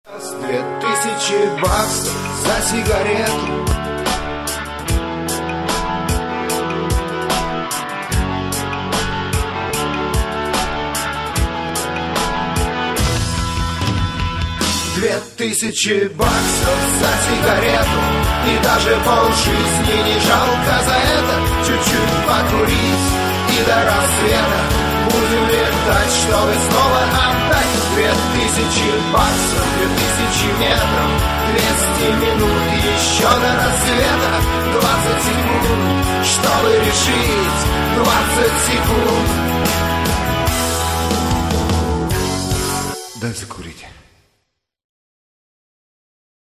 гитара
армейские
акустическая гитара